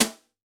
CYCdh_Kurz05-Brsh03.wav